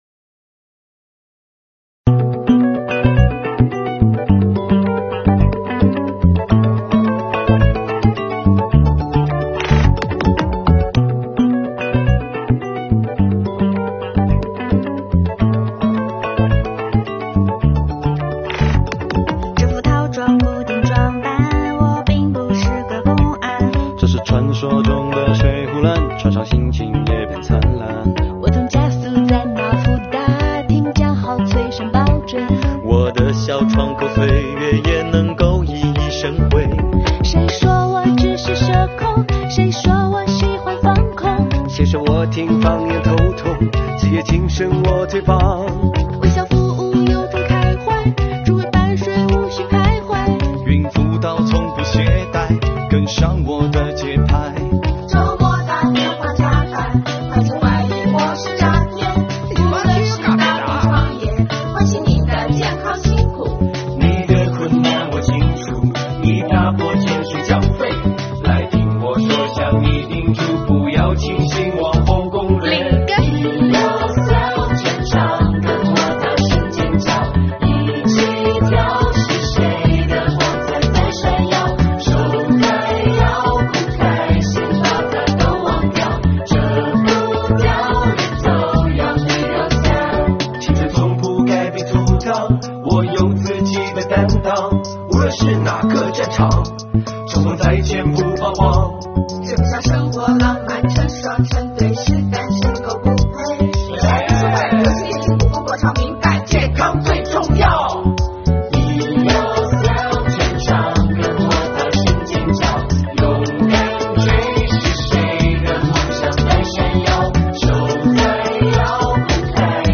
作品将税务青年的工作场景和一些温馨趣事编入其中，用镜头记录了税务青年忠诚担当的日常工作和活泼可爱的青春生活，更用镜头捕捉到他们充满勃勃生机、闪闪发光的青春风采。作品以MV的形式呈现，旋律轻松愉快，画面明亮活泼，歌词幽默欢乐。